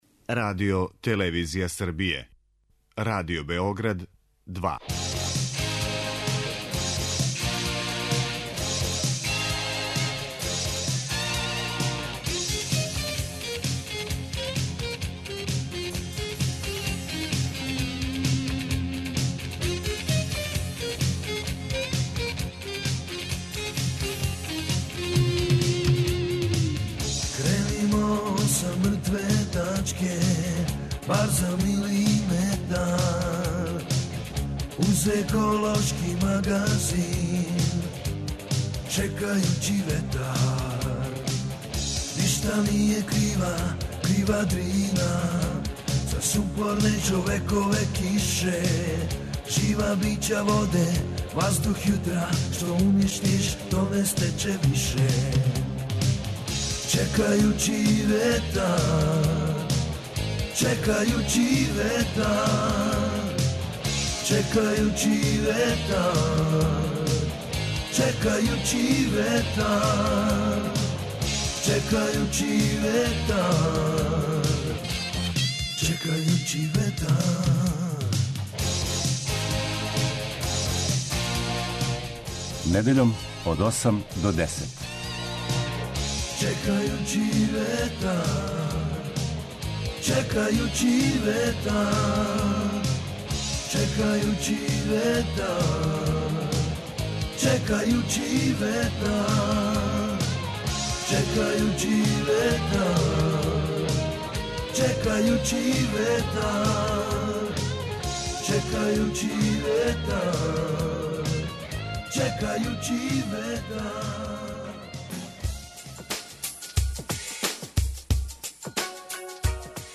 Чекајући ветар - еколошки магазин